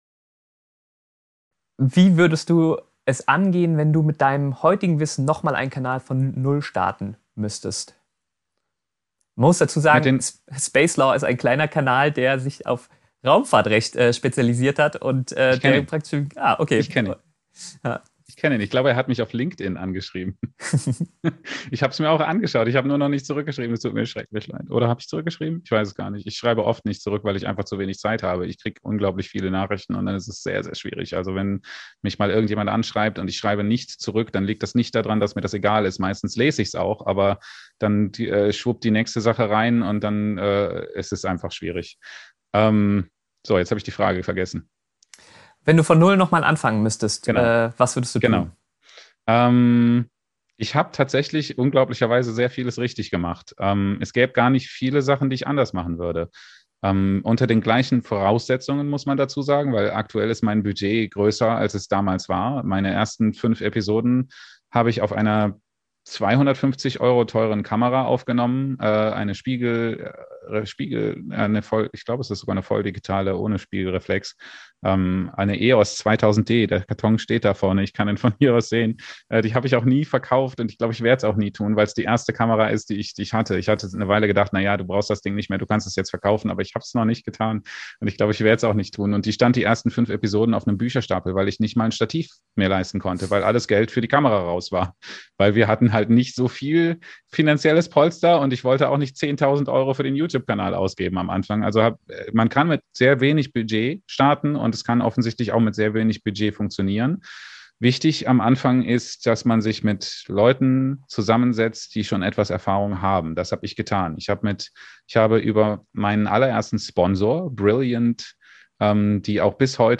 Ich hab den Livestream geschnitten und mit Untertiteln versehen.